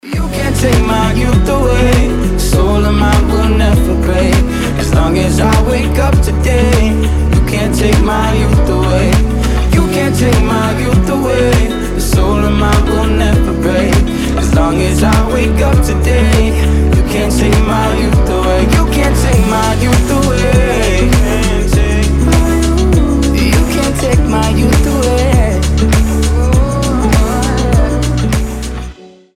• Качество: 320, Stereo
поп
красивый мужской голос
dance
RnB
теплые